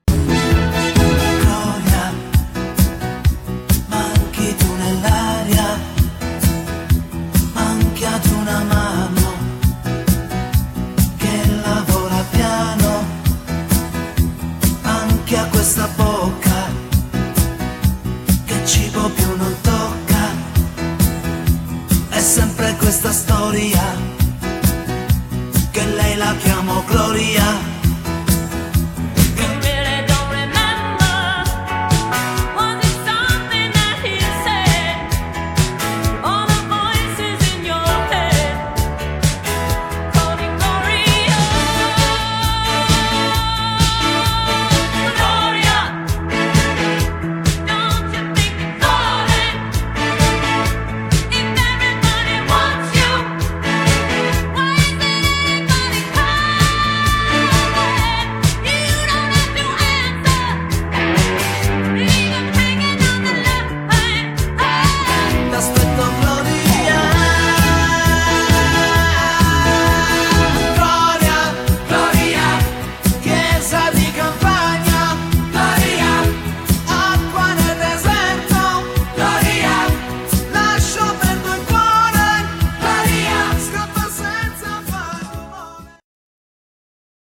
medley maison
elle s'époumone !